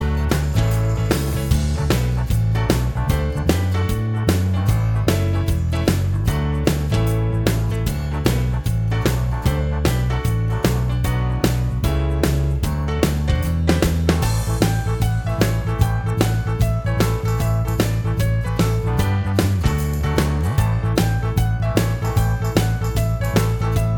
Minus Electric Guitar Pop (1970s) 3:25 Buy £1.50